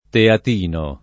teat&no] etn. (di Chieti) — voce lett. per chietino, passata all’ordine religioso dei padri teatini (o assol. teatini); usata ufficialmente col sign. originario in Strada Teatina, nome della statale 152, Chieti - Francavilla al Mare, e in Ripa Teatina, Torrevecchia Teatina, ecc., nome di centri abitati lì vicini — sim. il cogn. Teatini